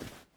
Crouch start 1.wav